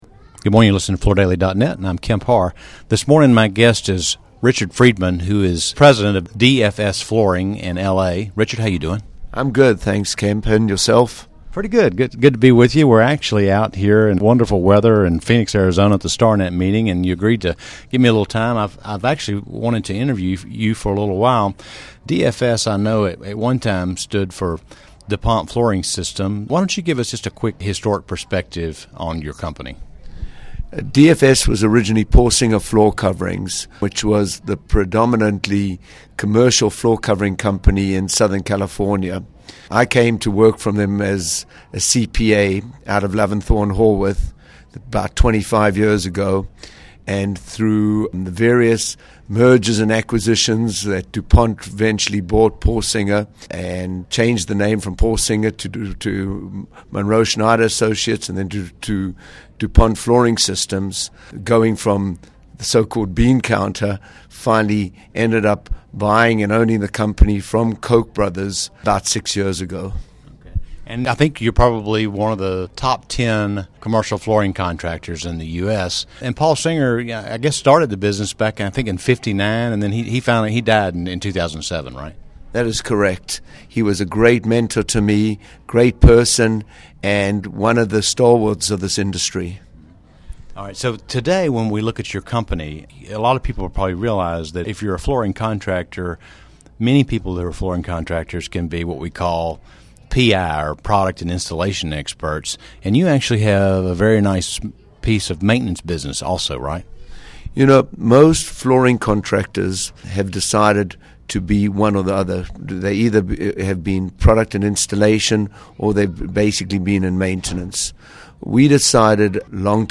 Listen to the interview to hear more about DFS' balance of maintenance to PI (product and installation) business.